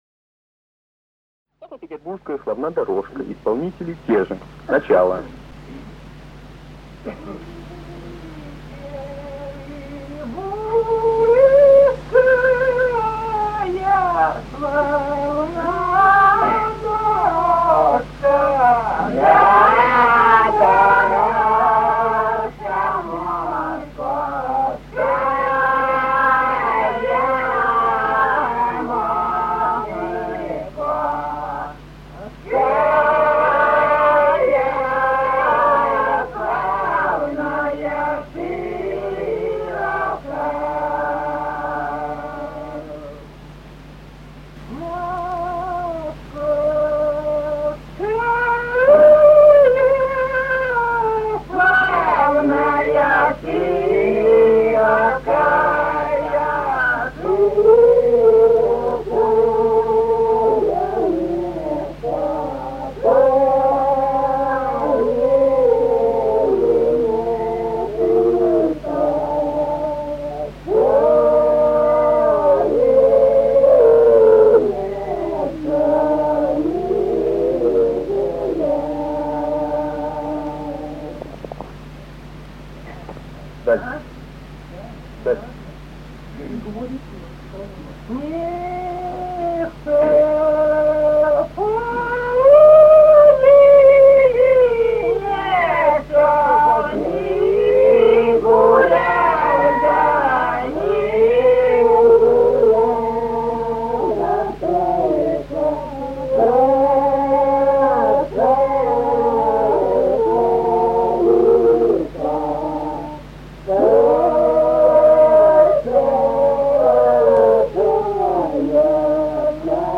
Русские народные песни Владимирской области 28. Петербургская славна дорожка (рекрутская протяжная) с. Михали Суздальского района Владимирской области.